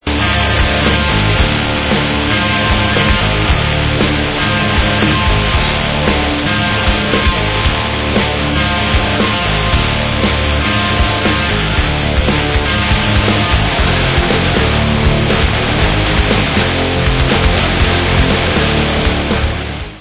sledovat novinky v oddělení Rock - Speed/Thrash/Death Metal